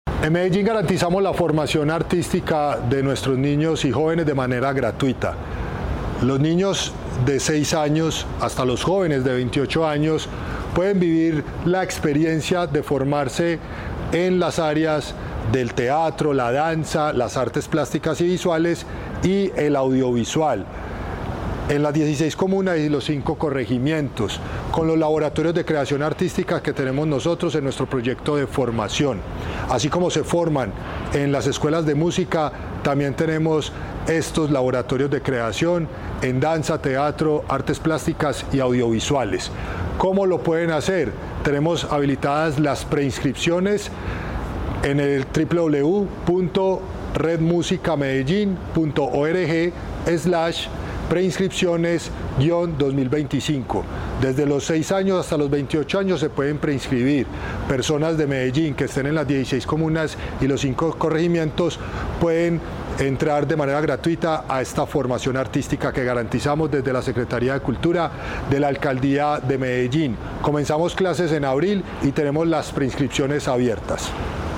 Palabras de Cristian Cartagena, subsecretario de Arte y Cultura La Alcaldía de Medellín anuncia que continúan abiertas las inscripciones para los 134 laboratorios de creación de la Red de Prácticas Artísticas y Culturales.